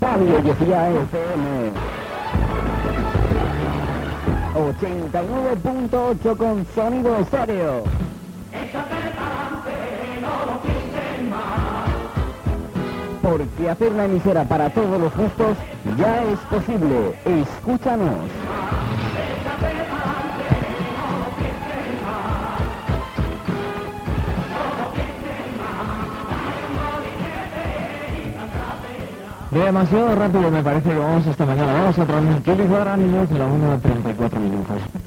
Indicatiu i hora